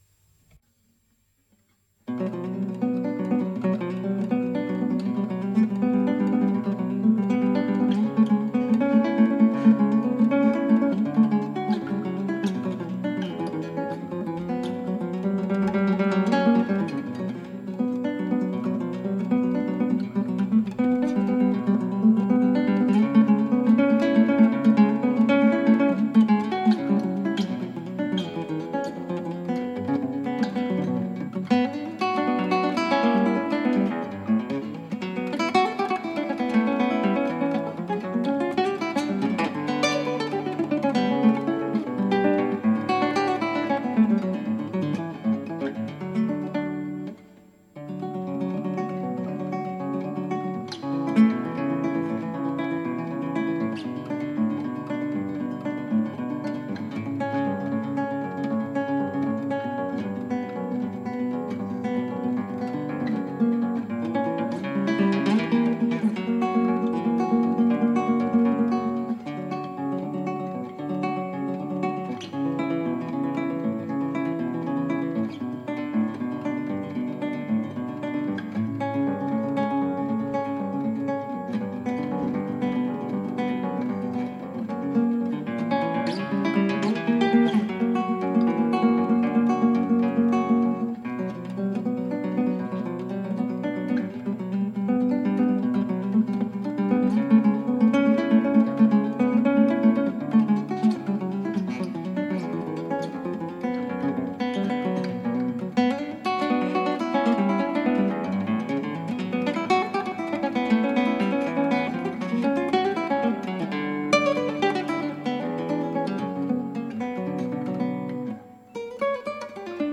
クラシックギター　ストリーミング　コンサート
さて、次のアレグロ・・・難しいので出来が悪いです。
トリをつとめるこのアレグロ、ミサの終わった後の雑踏を表現しているという話です。